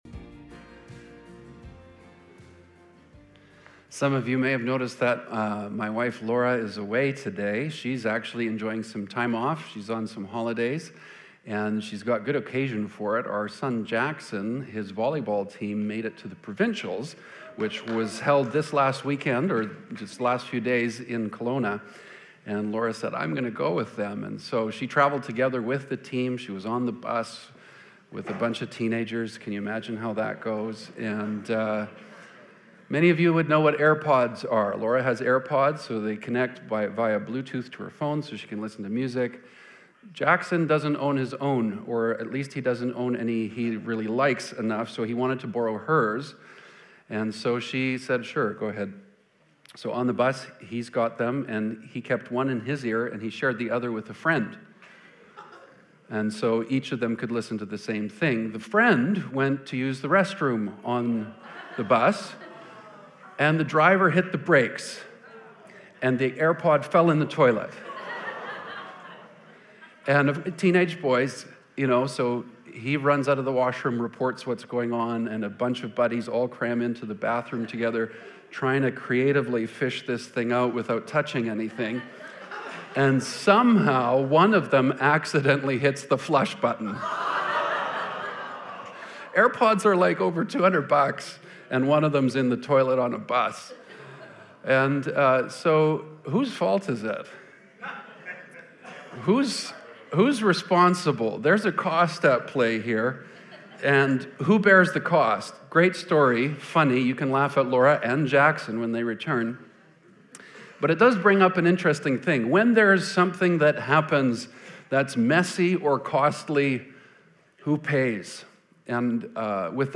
2024 Current Sermon Is The Gospel Actually Good News?